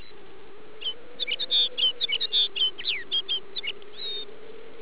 Click the picture to hear the Tundra.
tundrasound.au